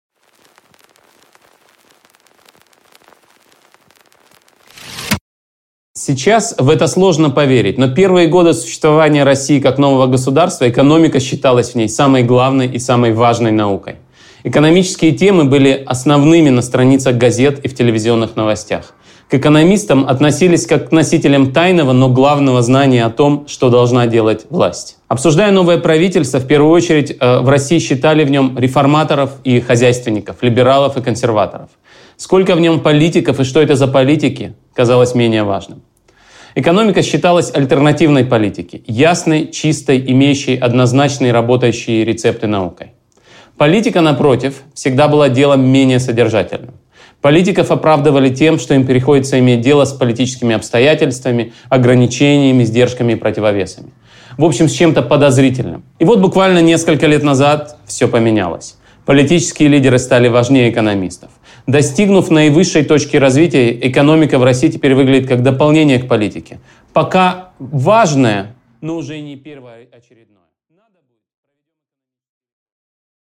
Аудиокнига Отчет о потерянном и найденном. Зачем нужно знать экономическую историю России | Библиотека аудиокниг